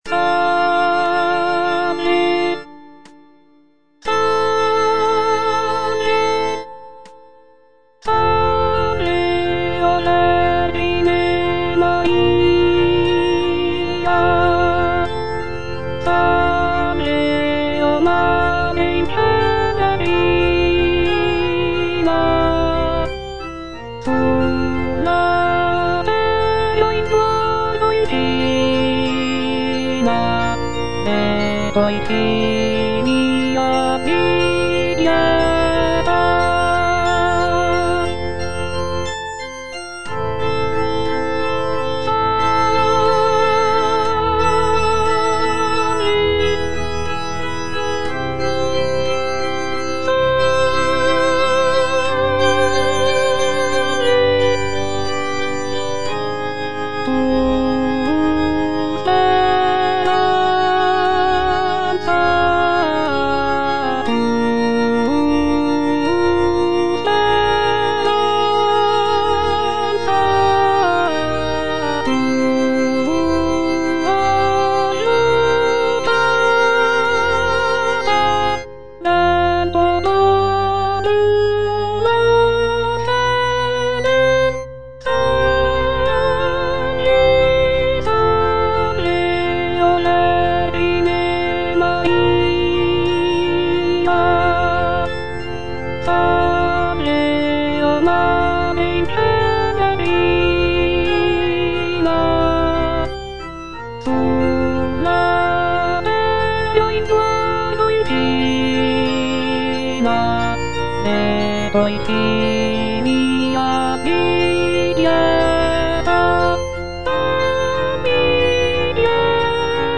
"Salve o vergine Maria" is a choral piece composed by Gioachino Rossini in 1831. It is a prayer to the Virgin Mary, and it is often performed during religious ceremonies. The music is characterized by its serene and devotional atmosphere, with lush harmonies and expressive melodies.